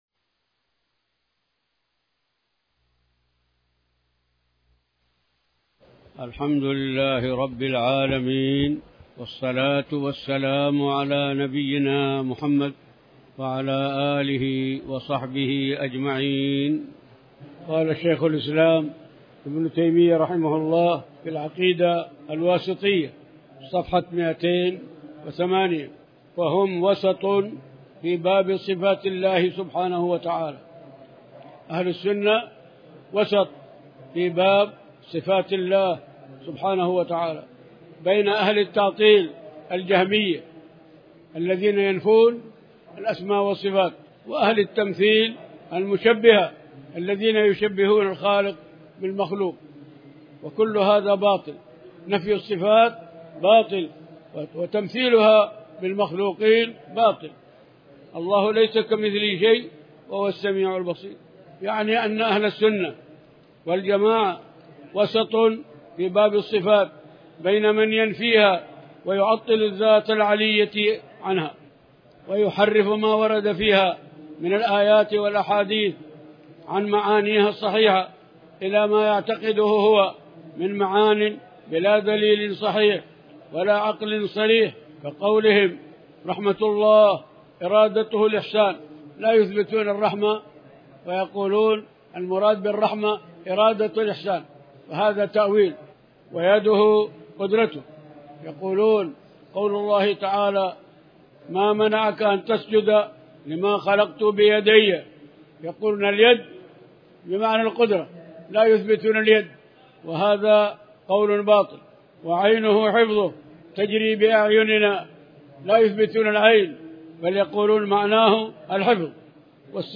تاريخ النشر ٦ محرم ١٤٤٠ هـ المكان: المسجد الحرام الشيخ